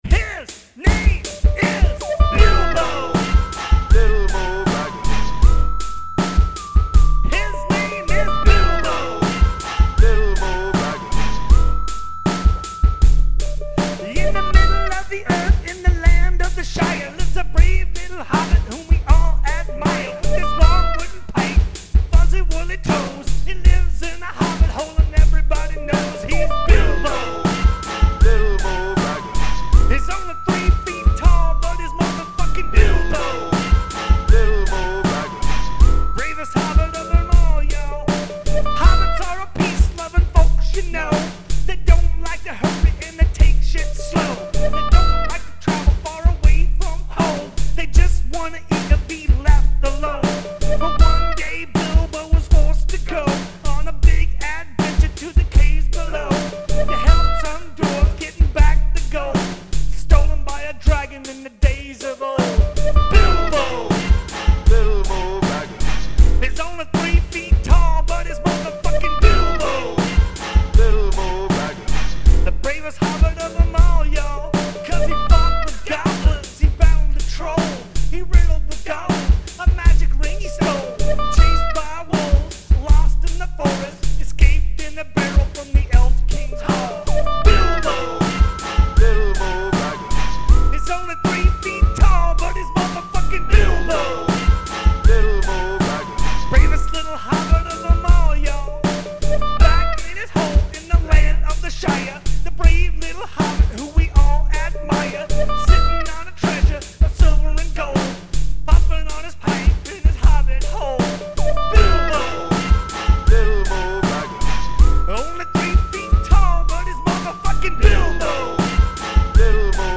White guy rap!